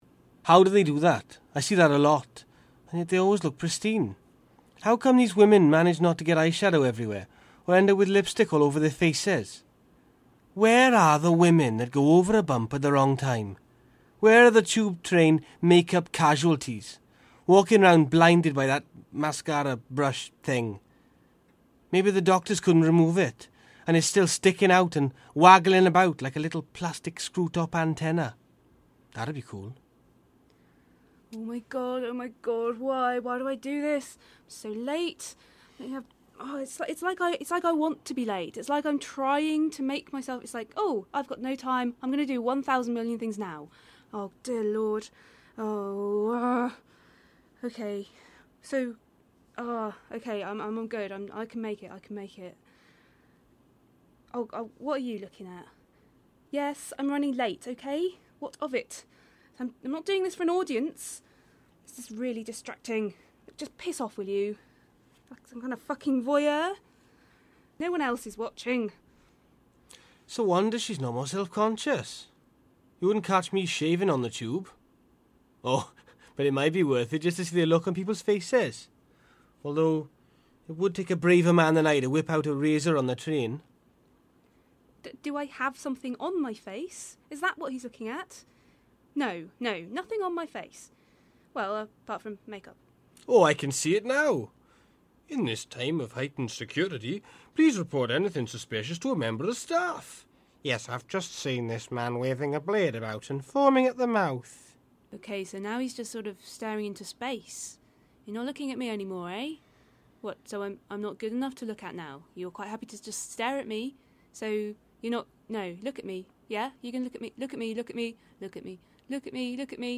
For this task I commissioned several writers and small theatre companies to create sketches inspired by travelling on the tube. The catch was, the audio would be pre-recorded and the action almost entirely silent.
Each sketch was segued with a fake station announcement for a made up tube line called the Oracle line.